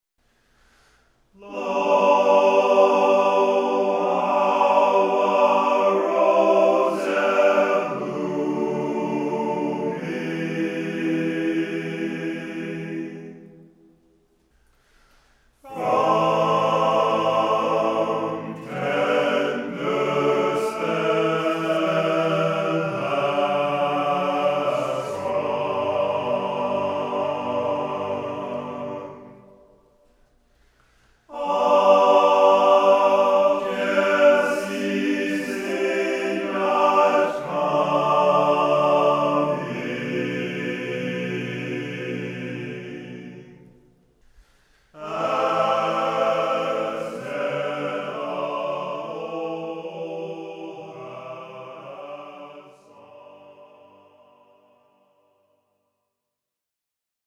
The sample a cappella sound clip for this article was recorded in a bathroom with a tile floor and a high ceiling. The microphone was placed in front of the tub enclosure for added natural reverb.
My recording was very free with tempo, so I used the breath intake on the previous tracks to get an idea of when to enter for the overdubs.
I achieved a blend across the stereo field by panning each successive overdub of the same voice part further away from the center point than it’s predecessor.
Using the natural reverb from the tile combined with the electronic reverb gave me the open yet contained sound I was looking for.
I used no compression on my recording at all.